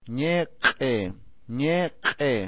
Elemplos: escuche ñiq'i y q'urawa que son abiertos a ñeq'e e q'orawa respectivamente.
Casi todas las palabras del aymara son llanas.